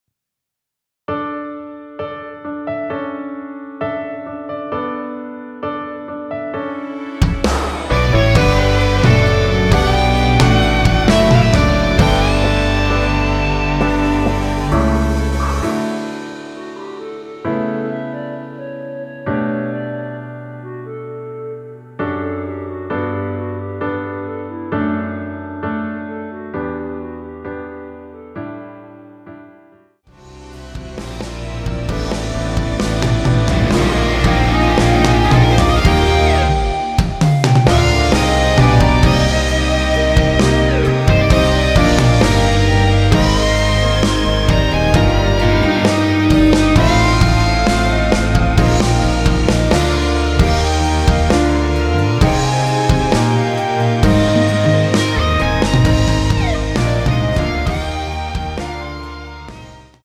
원키에서(+1)올린 멜로디 포함된 MR입니다.(미리듣기 확인)
◈ 곡명 옆 (-1)은 반음 내림, (+1)은 반음 올림 입니다.
노래방에서 노래를 부르실때 노래 부분에 가이드 멜로디가 따라 나와서
앞부분30초, 뒷부분30초씩 편집해서 올려 드리고 있습니다.
중간에 음이 끈어지고 다시 나오는 이유는